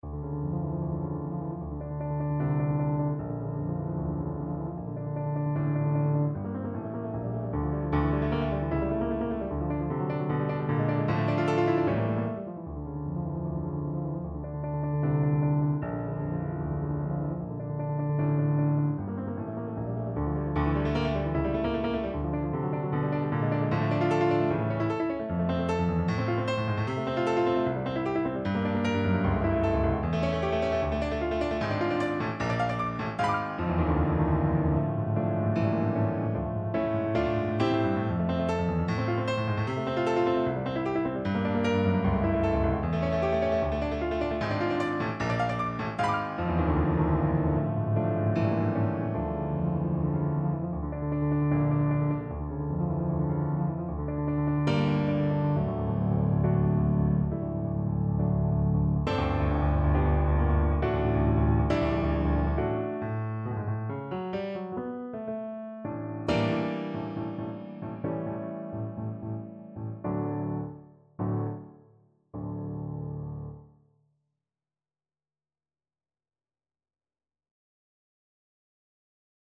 Free Sheet music for Piano
No parts available for this pieces as it is for solo piano.
F major (Sounding Pitch) (View more F major Music for Piano )
4/4 (View more 4/4 Music)
Allegro (=152) (View more music marked Allegro)
Piano  (View more Intermediate Piano Music)
Classical (View more Classical Piano Music)